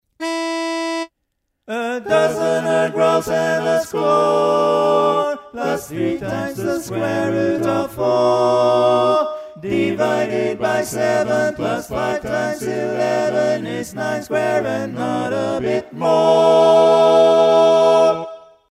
Key written in: E Major
Type: Barbershop